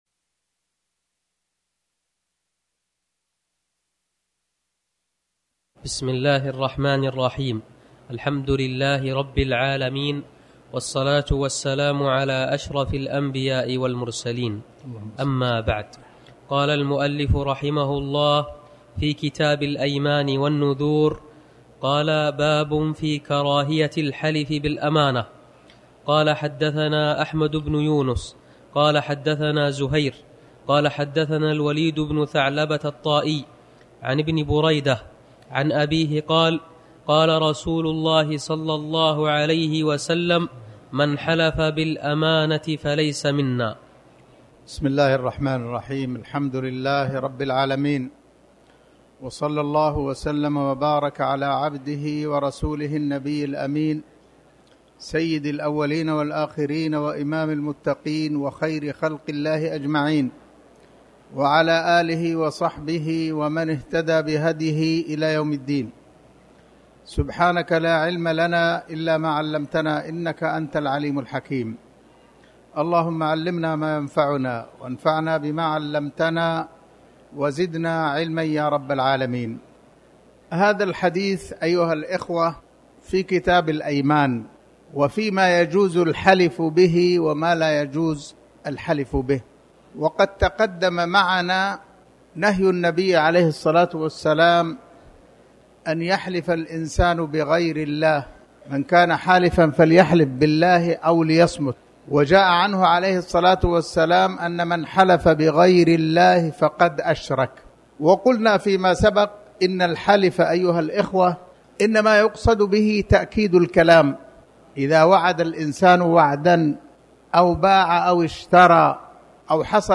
تاريخ النشر ١٥ ربيع الثاني ١٤٤٠ هـ المكان: المسجد الحرام الشيخ